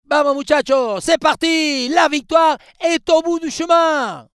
Waze vous offre les voix des nouveaux commentateurs du jeu FIFA 23 (Omar da Fonseca et Benjamin Da Silva)
Dans votre voiture, équipé de l’application de navigation, vous pourrez donc vivre l’ambiance d’un match de foot au volant en installant les voix du duo de beIN SPORTS.